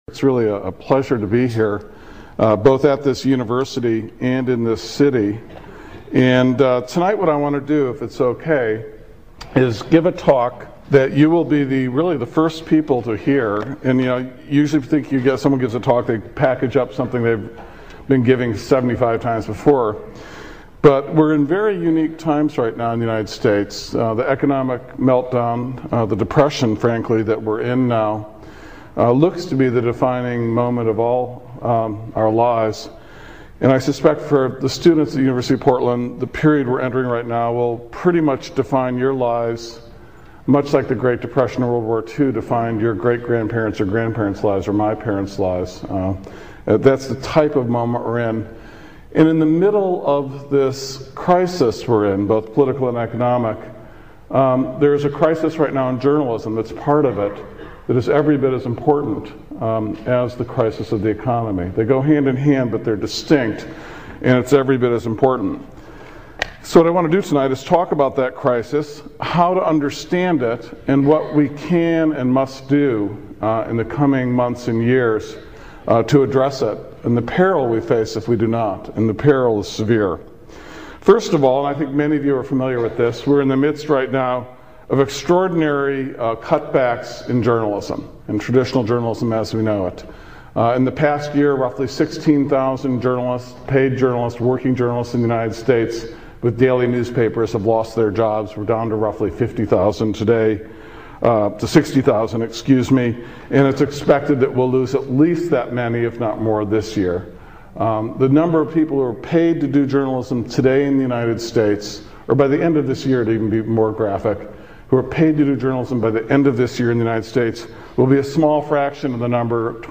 University of Portland talk